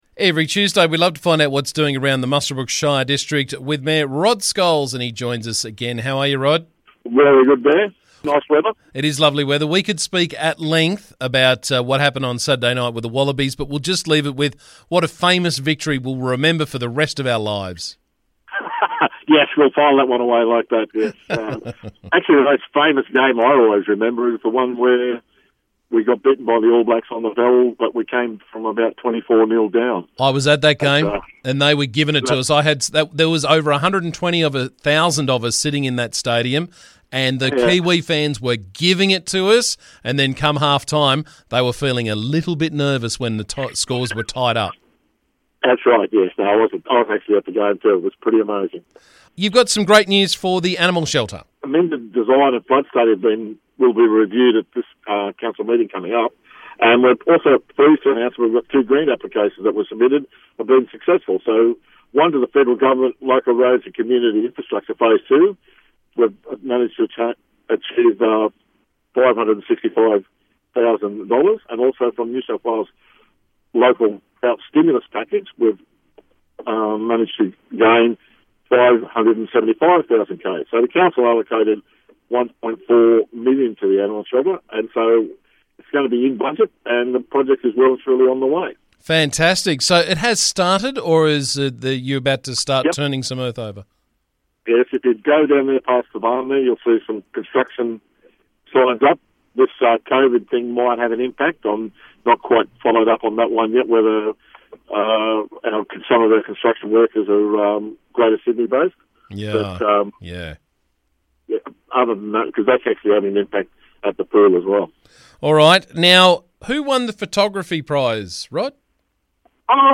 Muswellbrook Shire Council Mayor Rod Scholes joined me to talk about the latest from around the district.